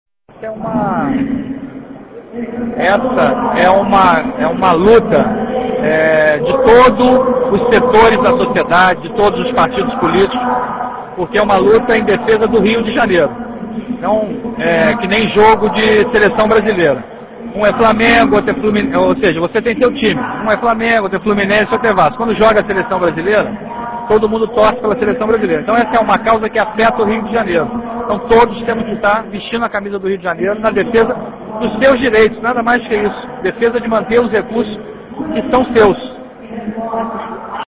Áudio – ANDRÉ CORRÊA comenta a questão dos Royalties
defesa_dos_royalties_do_petroleo_passeata.mp3